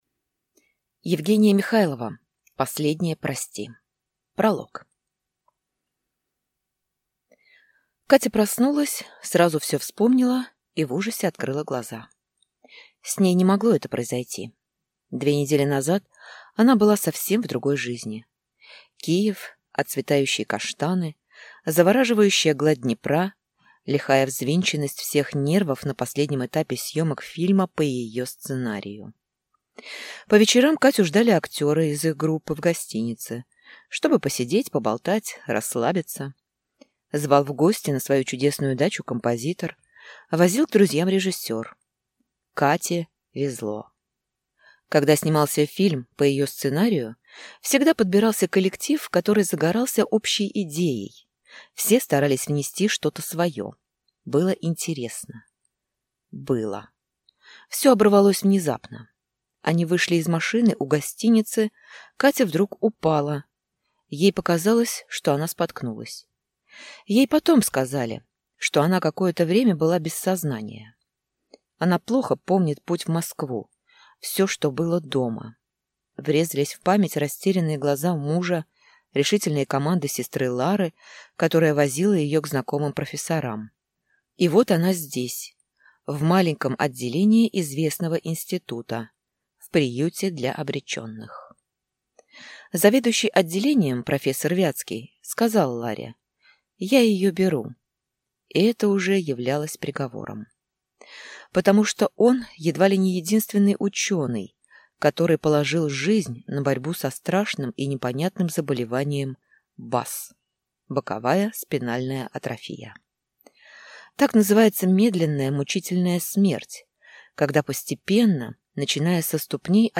Аудиокнига Последнее прости | Библиотека аудиокниг